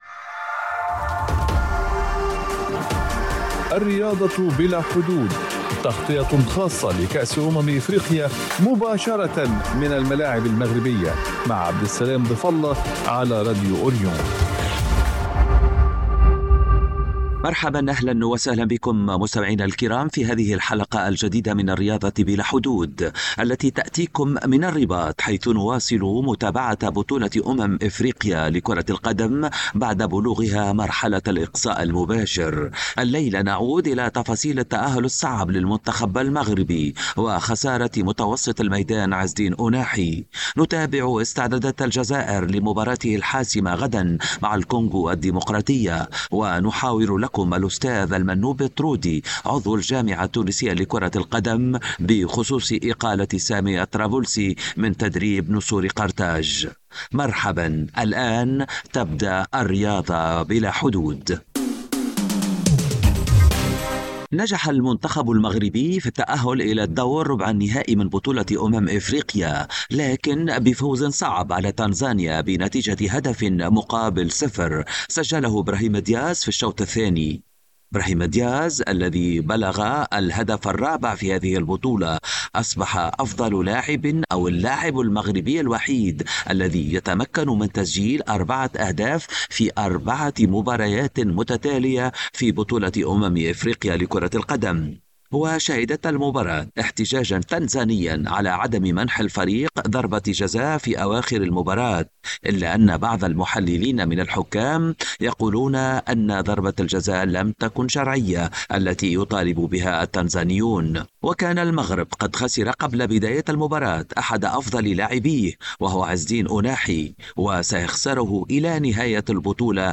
في هذه الحلقة الجديدة من الرياضة بلا حدود التي تأتيكم من الرباط حيث نواصل متابعة بطولة أمم افريقيا بعد بلوغها مرحلة الإقصاء المباشر .